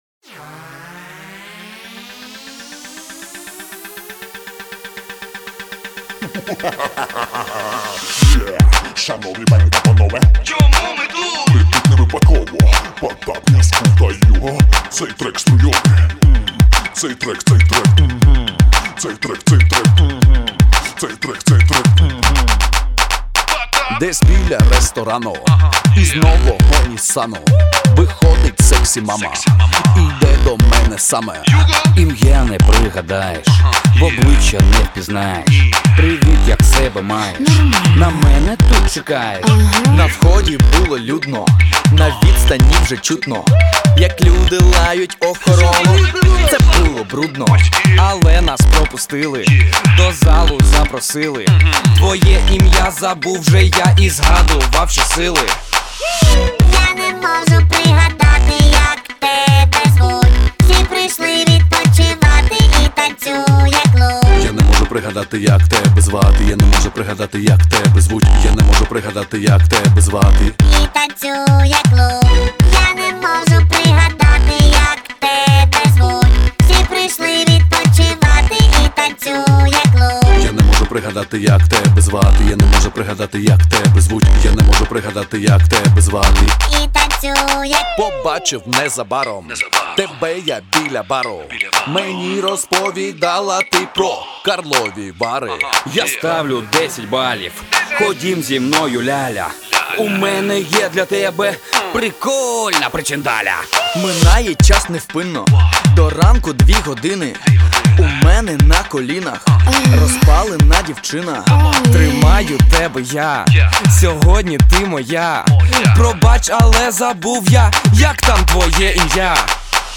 Скачать Rap, Hip-Hop альбомы и треки.